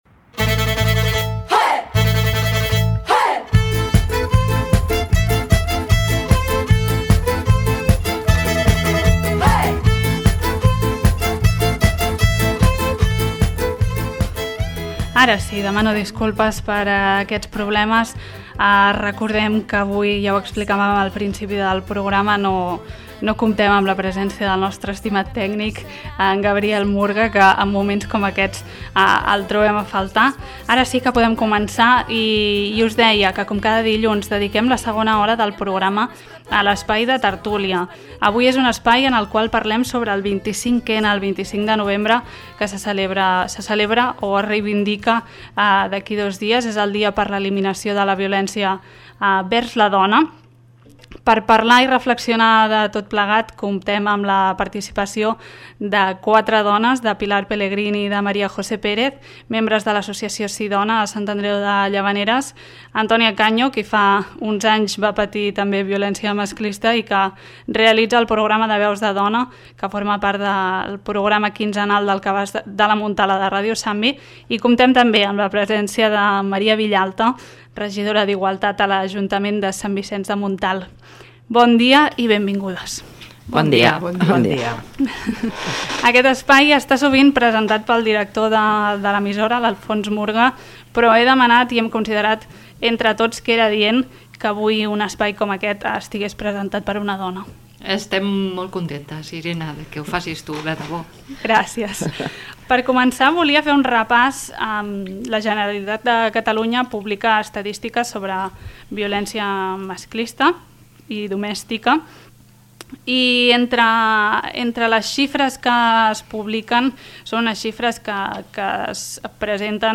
Compartim tert�lia sobre viol�ncia masclista
Compartim la tert�lia que va emetre ahir R�dio Santvi coincidint amb el Dia Internacional contra la viol�ncia masclista.